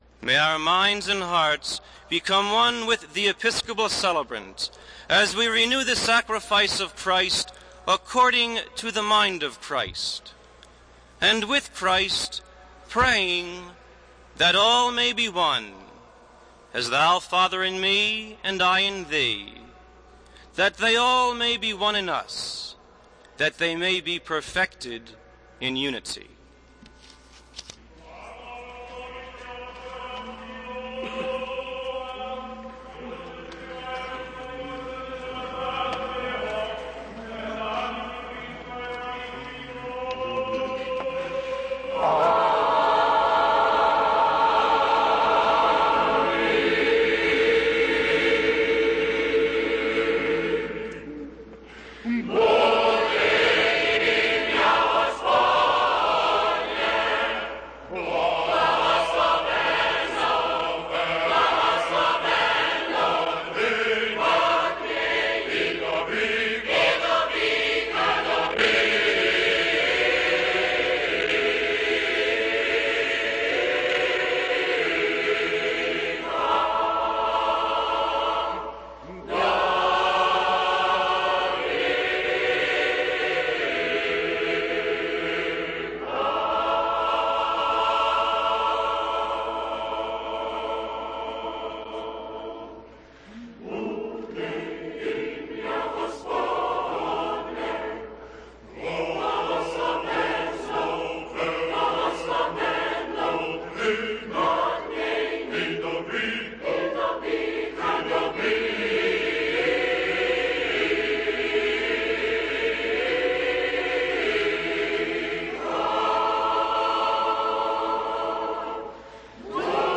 High Mass
Beginning of the archpastoral liturgy
First antiphon
Trisagion
Gospel reading